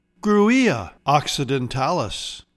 Pronounciation:
Grew-EE-a ox-i-den-TAL-is